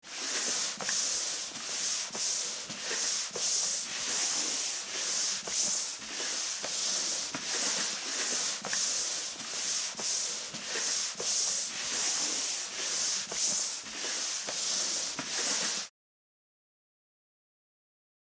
Download Free Cleaning Sound Effects | Gfx Sounds
Wood-carpet-mopping-a-wooden-floor-cleaning.mp3